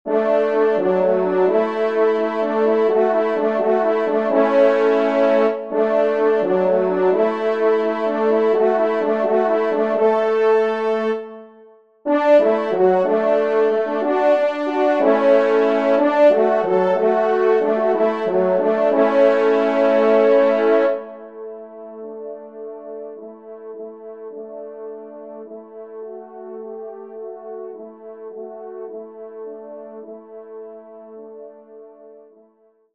Genre :  Divertissement pour Trompes ou Cors
3ème Trompe